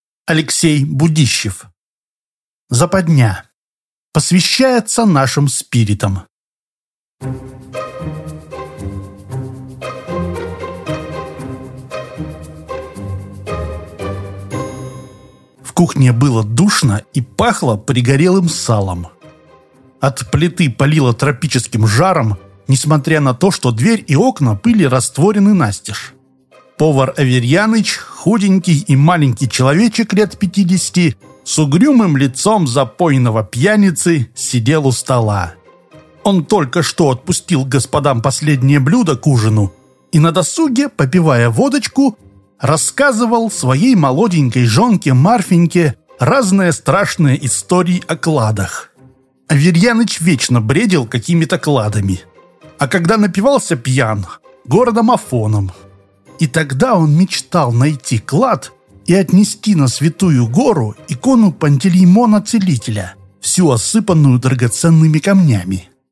Aудиокнига Западня